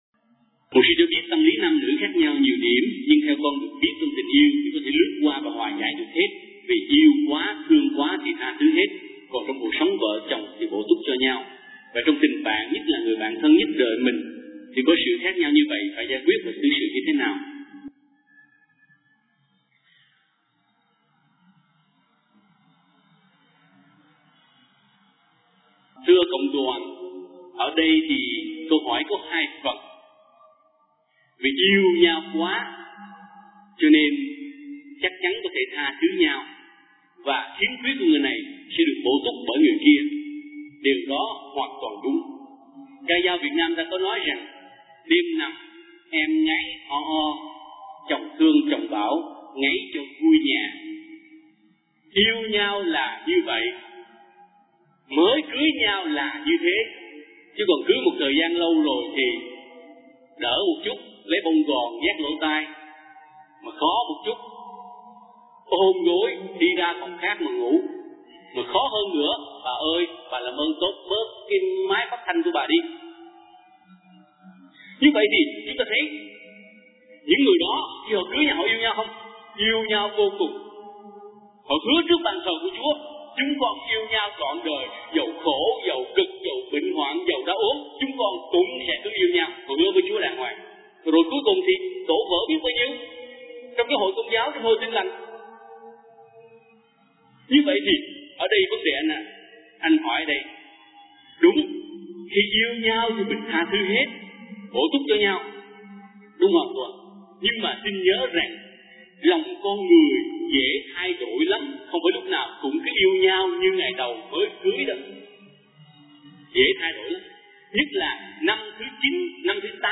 * Ca sĩ: Mục sư
* Thể loại: Nghe giảng